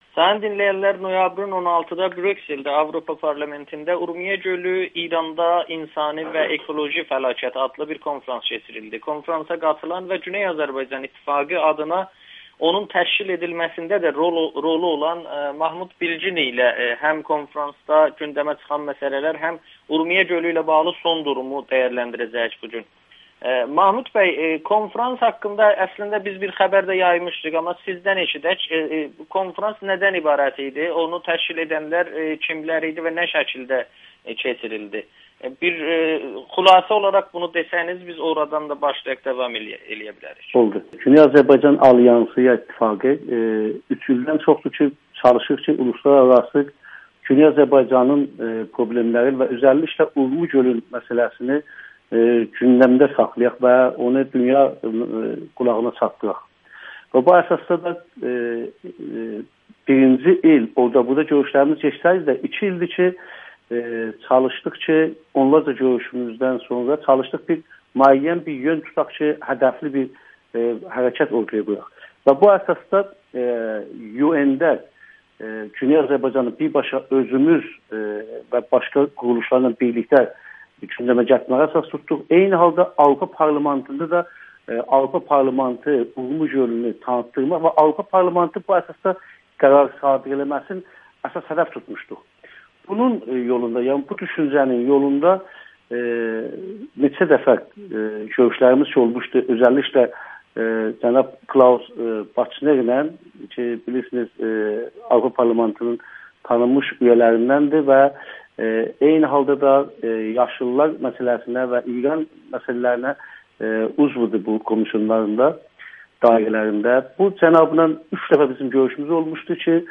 Hakimiyyətə basqı olunmasa Urmiyə Gölü böhranı çözülməyəcək [Audio-Müsahibə]